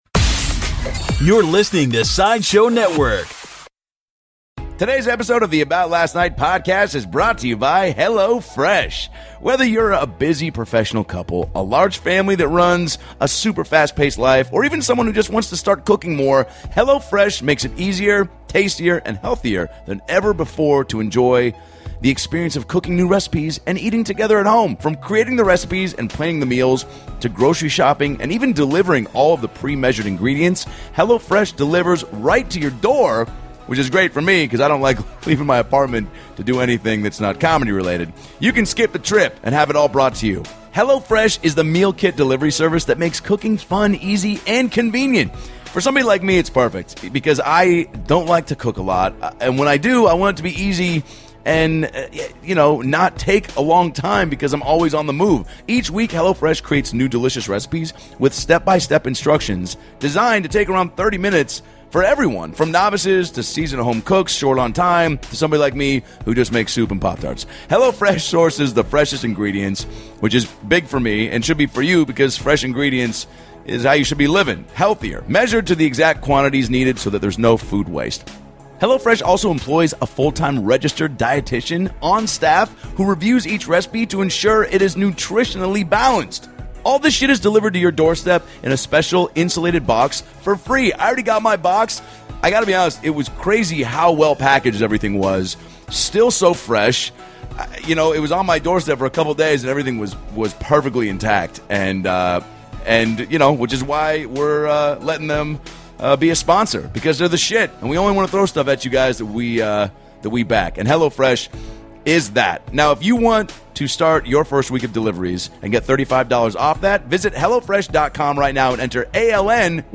is a master impressionist, and with spot on impressions of Donald Trump, Bernie Sanders, Jeff Goldblum, Steve Buscemi, George Lucas, and Ian McKellan, he had the guys laughing the whole time!